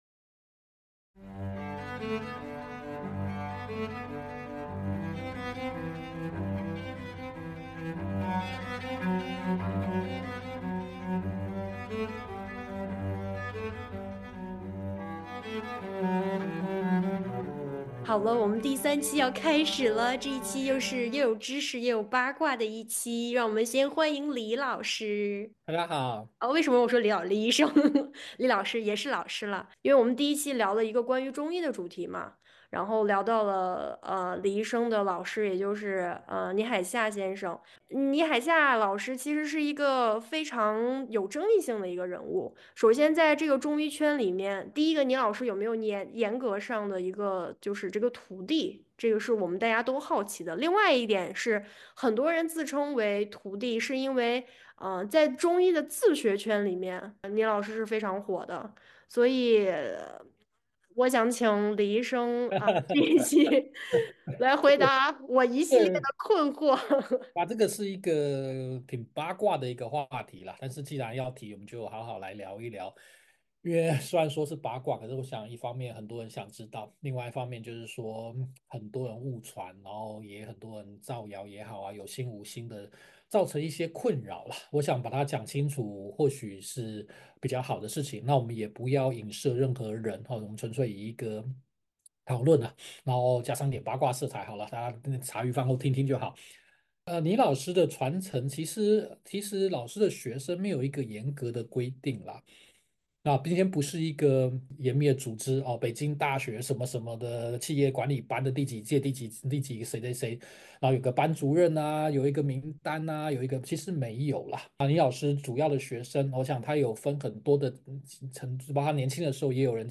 目前沒有特定的題材，也沒有特定的時間表，隨性也隨時間，藉由主持人的提問，來和大家聊一聊。